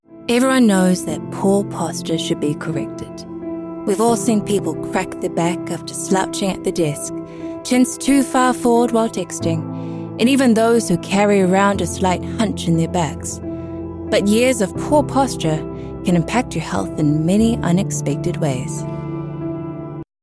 Has Own Studio
psa